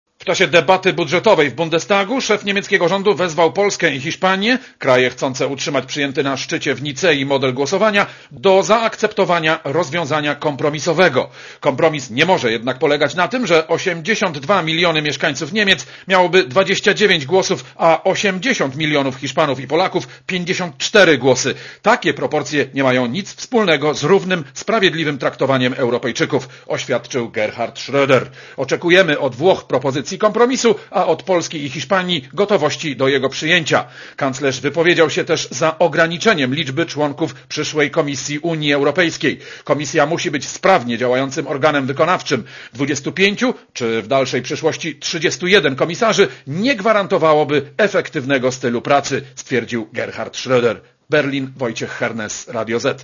Korespondencja z Berlina (208Kb)